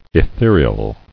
[e·the·re·al]